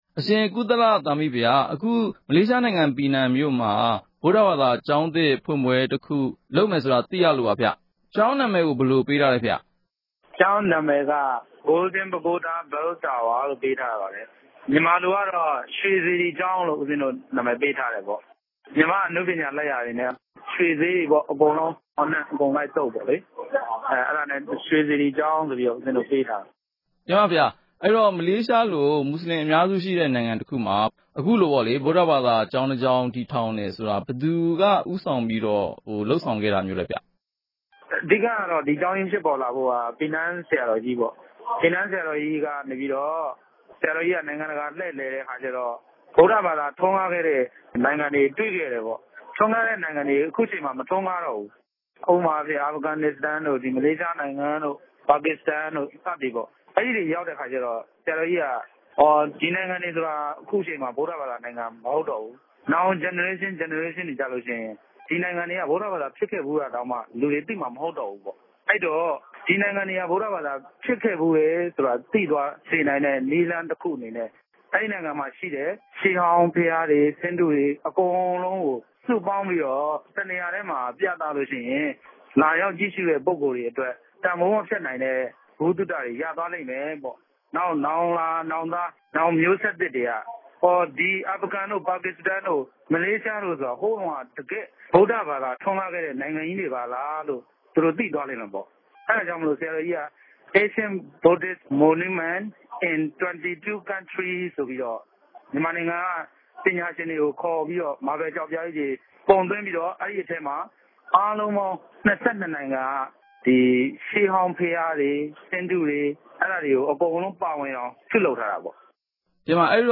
ဆက်သွယ်မေးမြန်းချက်။